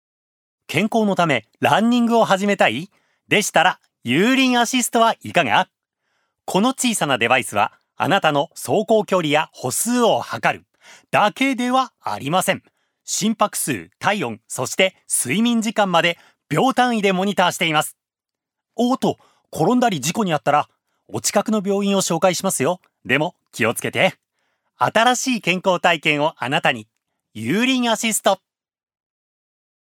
所属：男性タレント
ナレーション５